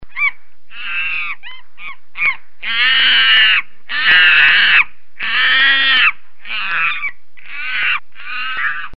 Звуки обезьян
Болтовня обезьяны